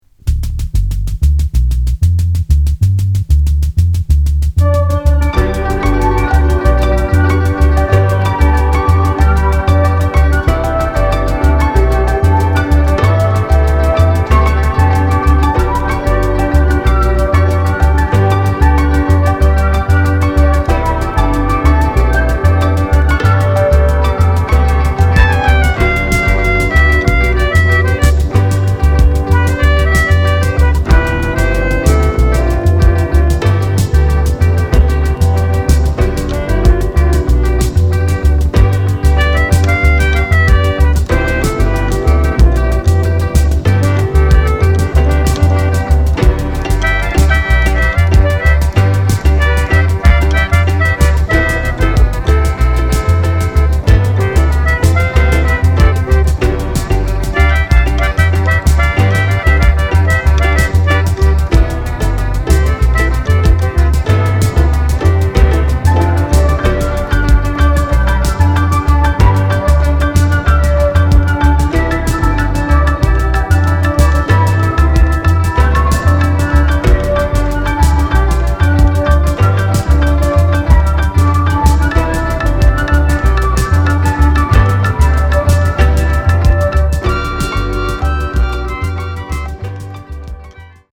宅録　ミニマル